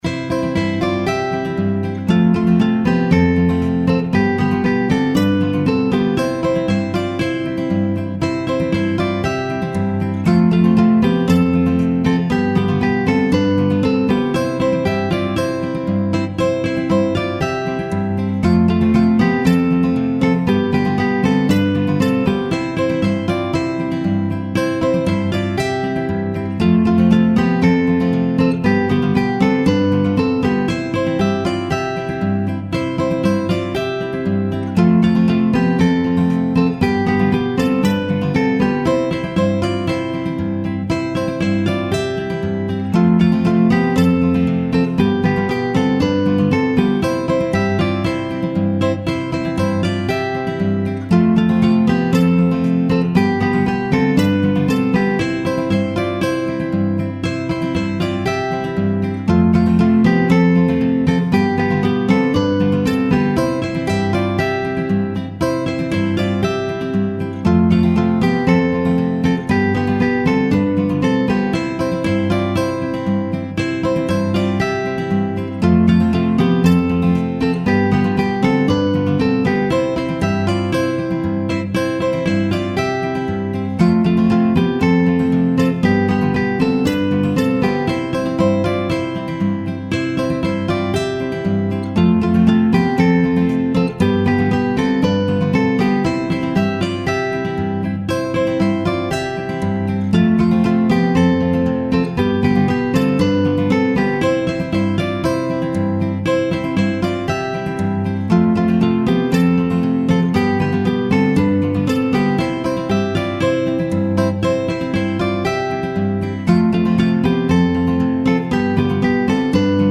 SOFT
明るい  ほのぼの  爽やか